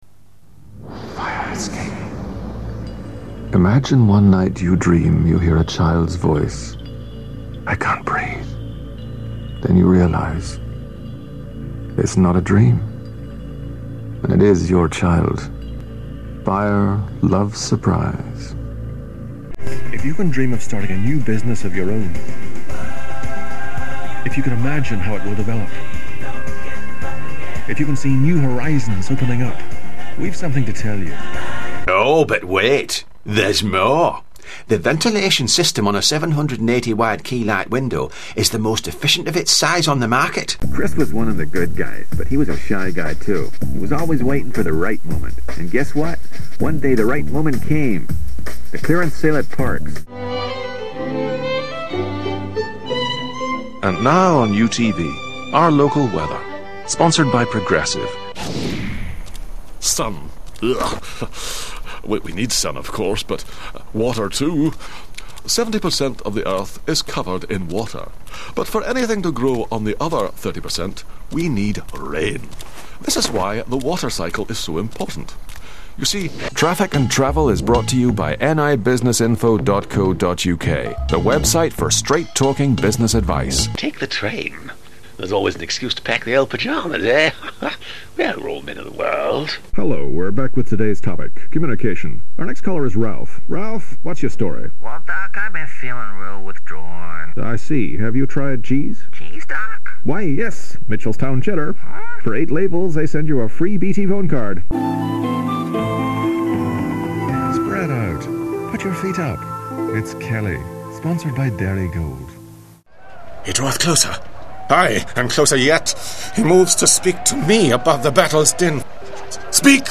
Adult, Male Artists, Northern Ireland
His skilled and polished reads are slick and textured.
Demo(s)